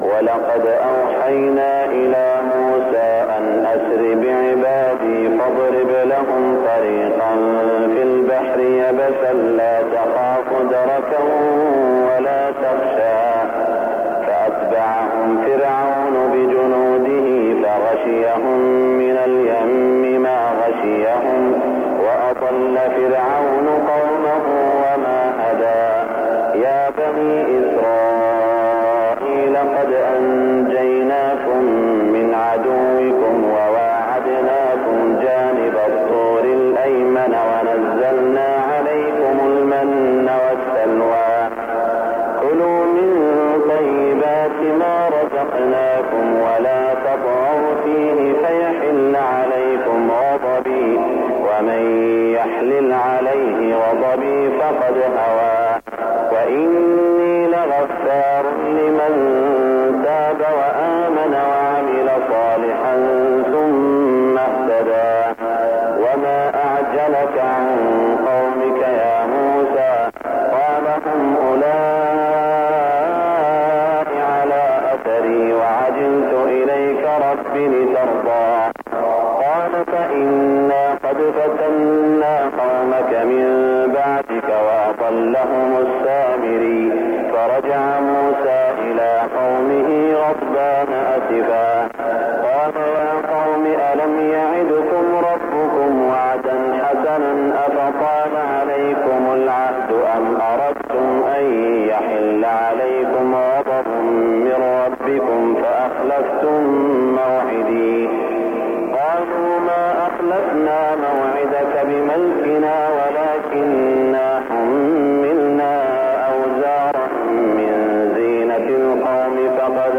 صلاة الفجر 1418هـ من سورة طه > 1418 🕋 > الفروض - تلاوات الحرمين